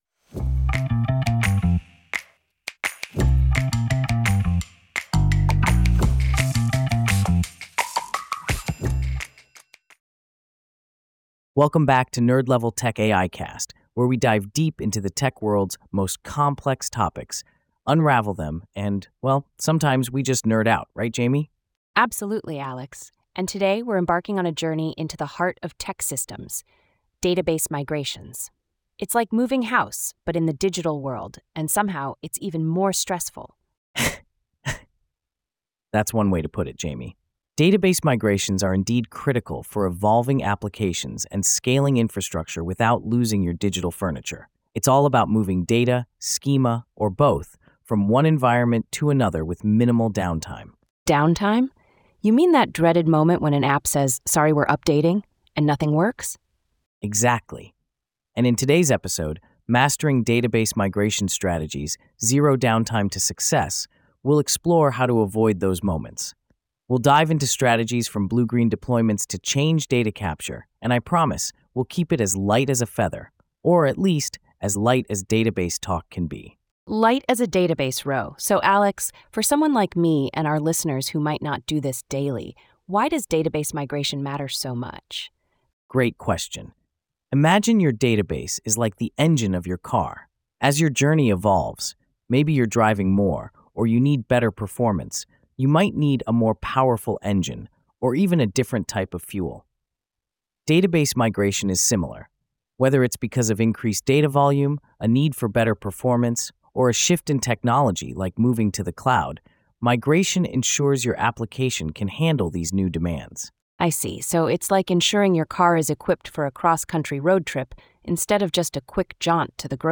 AI-generated discussion